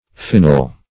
Phenyl \Phe"nyl\, n. [Gr. fai`nein to bring to light + -yl: cf.